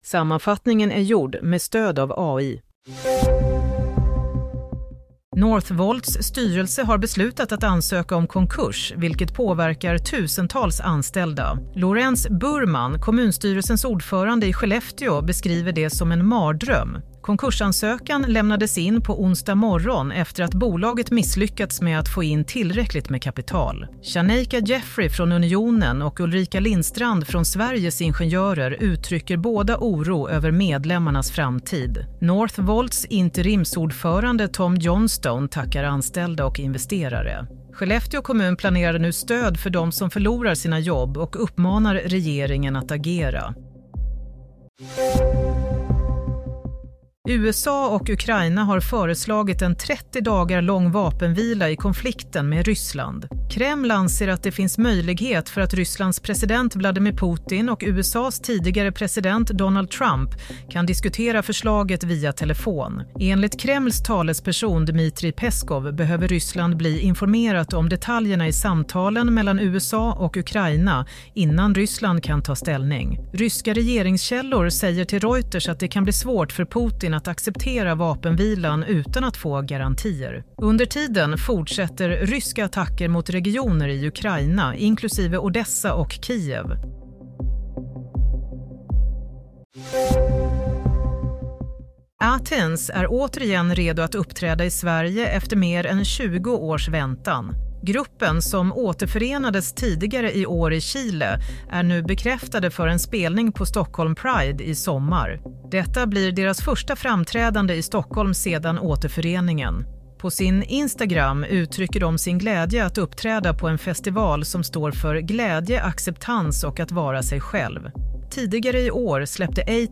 Play - Nyhetssammanfattning – 12 mars 16:00